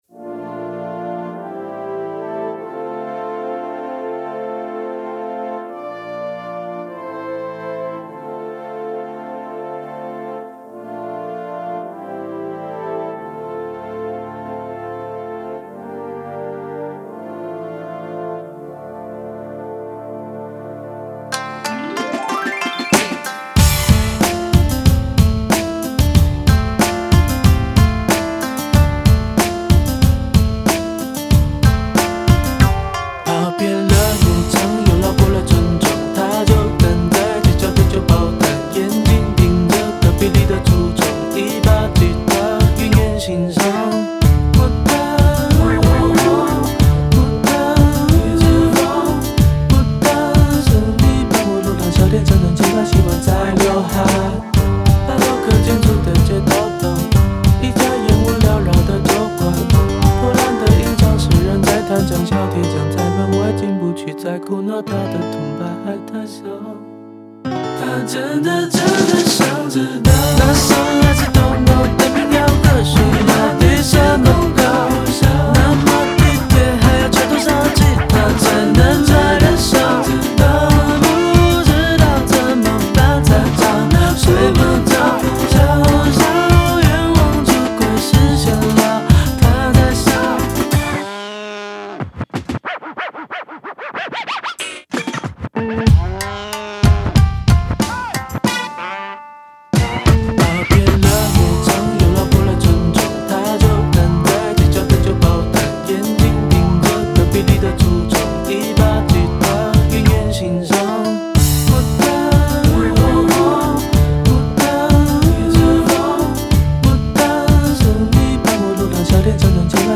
架子鼓
架子鼓谱